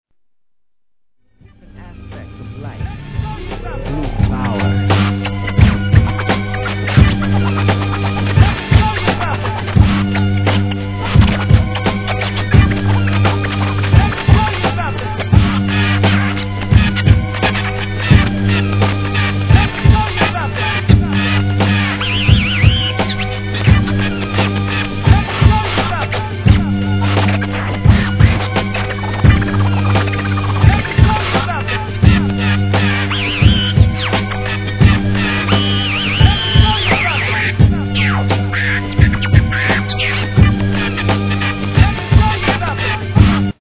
HIP HOP/R&B
サンフランシスコ発のアンダーグランドHIP HOP!!